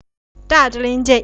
Retroflex
Ta-ta-lin-chait  {Ta.þûn-lying:hkyait}